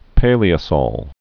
(pālē-ə-sôl, -sŏl)